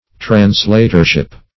Translatorship \Trans*lat"or*ship\, n. The office or dignity of a translator.